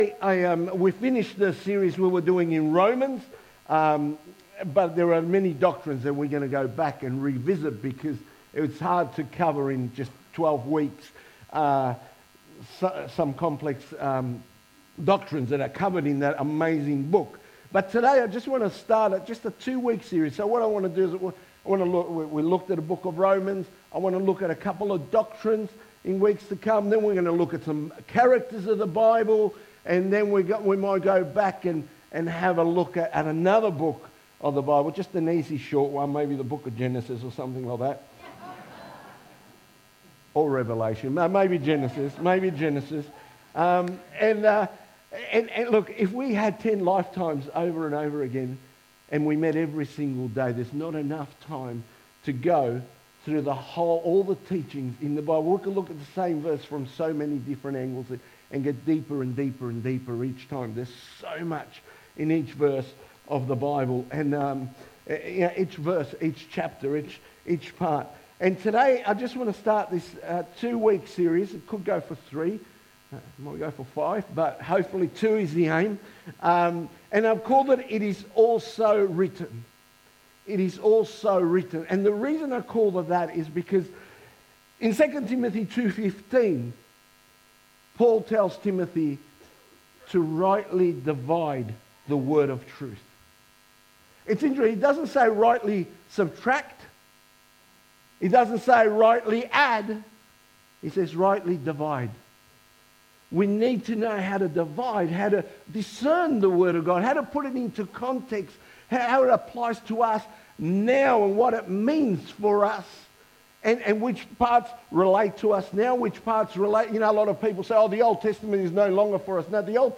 2025 • 27.96 MB Listen to Sermon Download this Sermon Download this Sermon To download this sermon